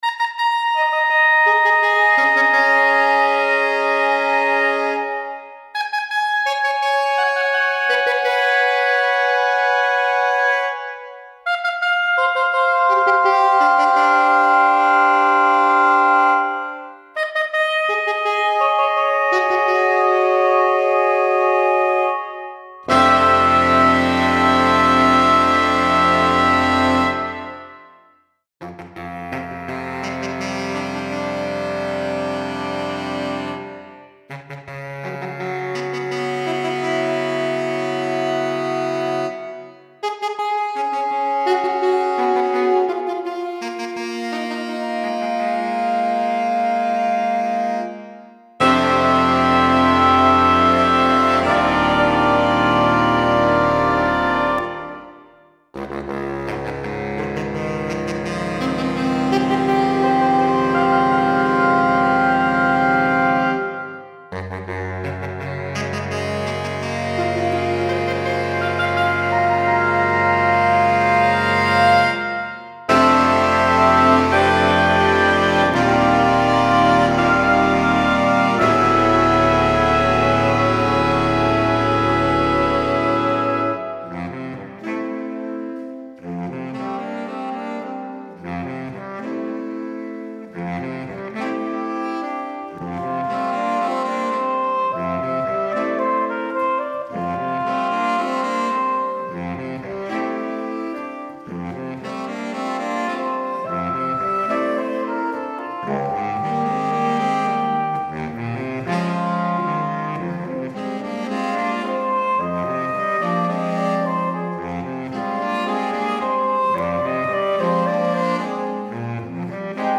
Contemporary
A haunting and atmospheric work for saxophone ensemble
Sopranino, Soprano, Alto, Tenor, Baritone
Bass and Contrabass